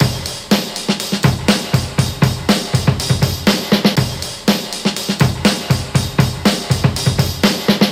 • 121 Bpm Drum Groove B Key.wav
Free drum groove - kick tuned to the B note. Loudest frequency: 3329Hz
121-bpm-drum-groove-b-key-udv.wav